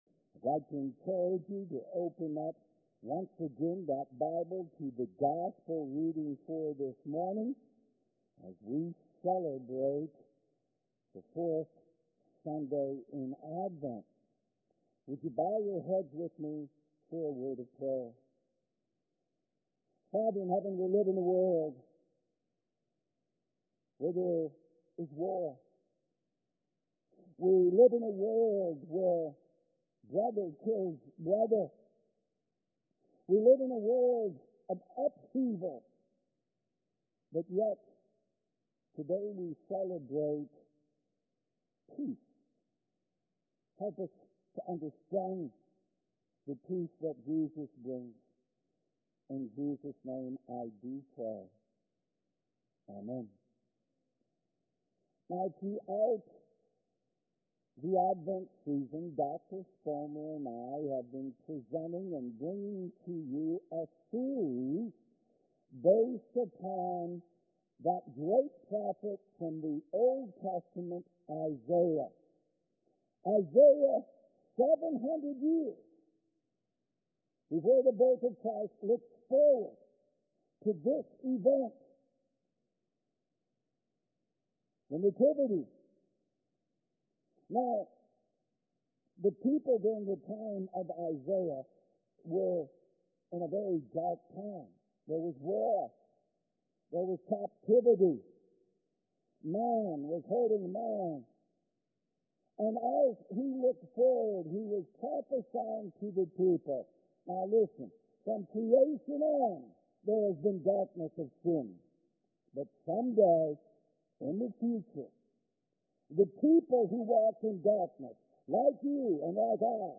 Message Listen Service Christmas is one week away.